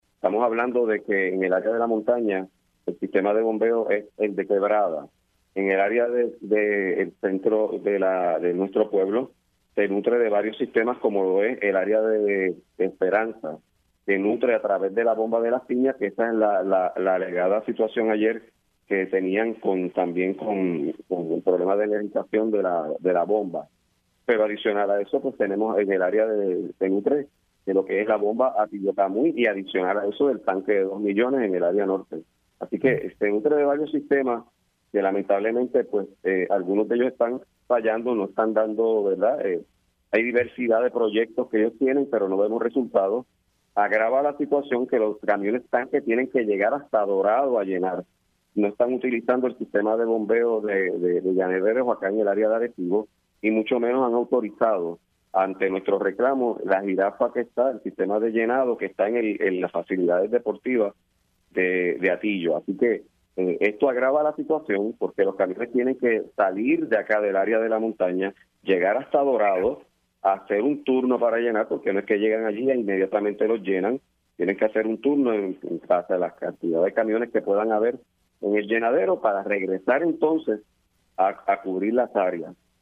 El alcalde de Hatillo, Carlos Román denunció en Pega’os en la Mañana que varias zonas en su municipio – incluyendo Buena Vista, Mariposa, Campo Alegre, Carrizales, Aibonito y Bayané – tienen más de 70 días sin agua potable.